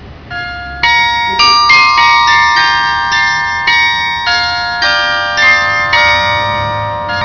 TU Clausthal - Angewandte Photonik: Glockenspiel
Klangbeispiel Zellerfeld
zellerfeld-7-1.wav